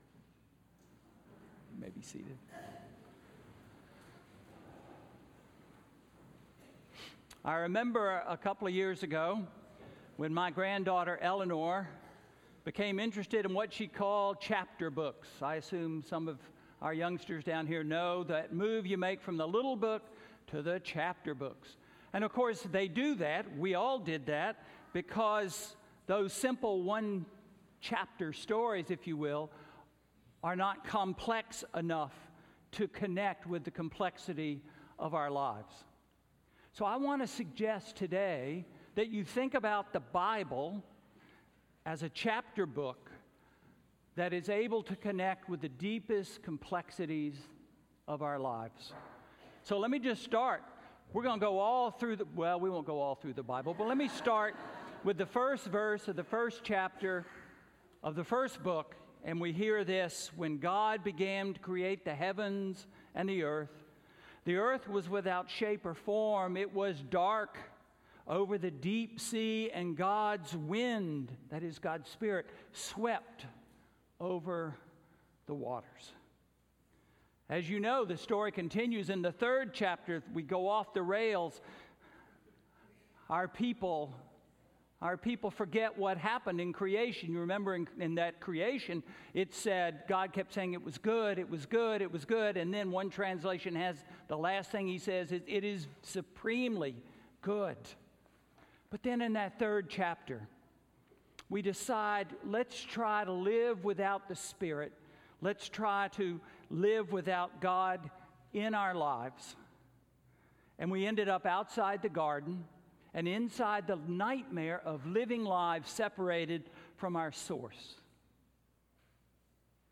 Sermon–Raise a Sail for the Wind of God?